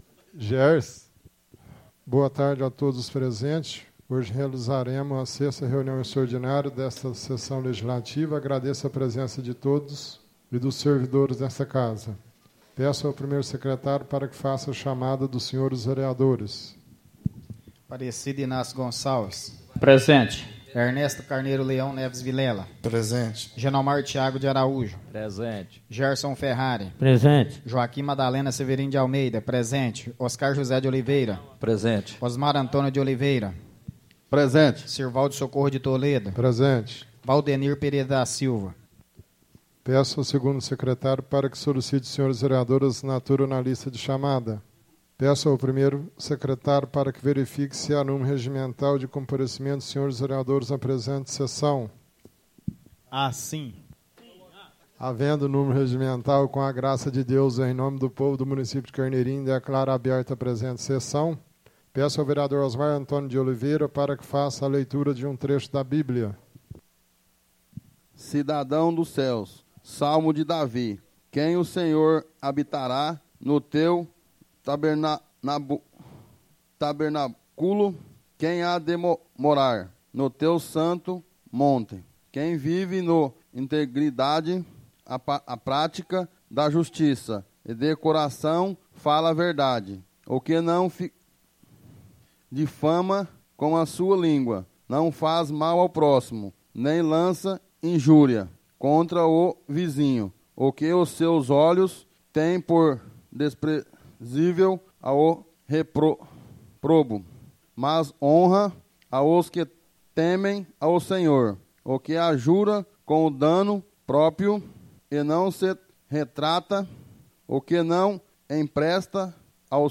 Áudio da 6ª (sexta) sessão extraordinária de 2015, realizada no dia 24 de Novembro de 2015, na sala de sessões da Câmara Municipal de Carneirinho, Estado de Minas Gerais.